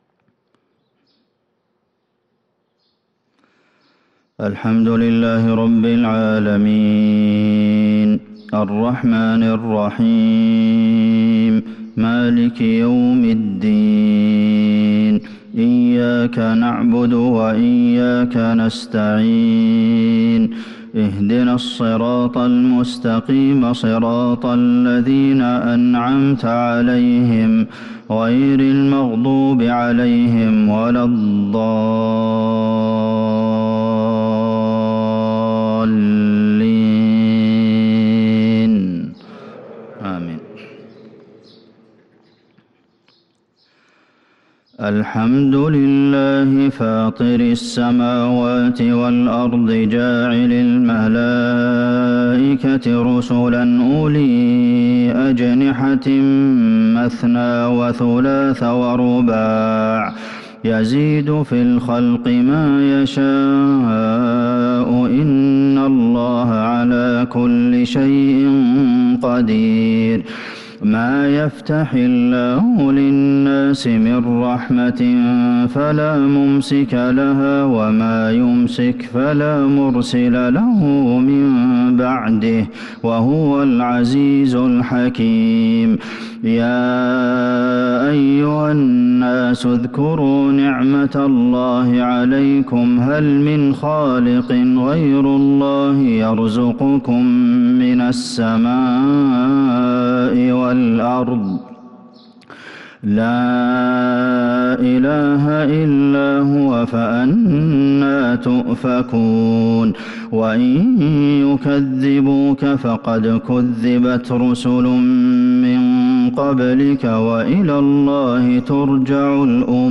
فجر الجمعة 4-6-1443هـ فواتح سورة فاطر | Fajr prayer from Surat Fatir 7-1-2022 > 1443 🕌 > الفروض - تلاوات الحرمين